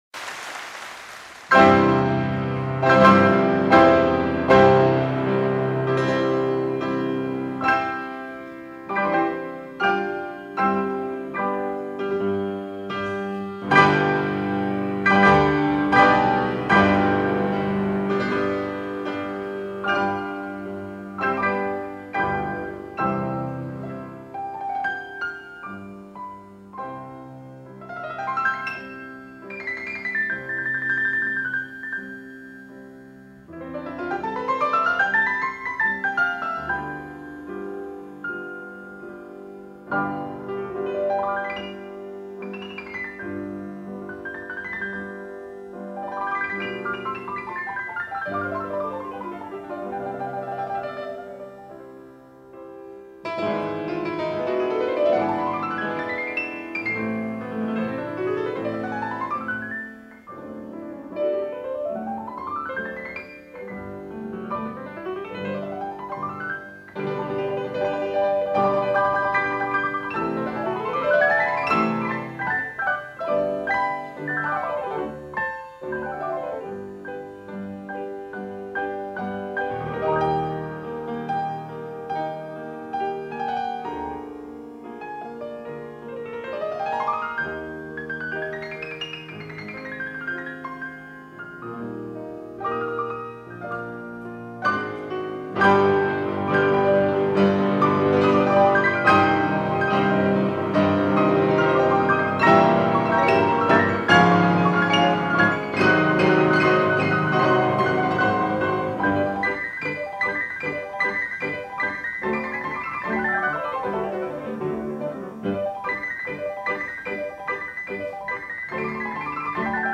Carl Czerny - Quartet for 4 pianos in C major part1.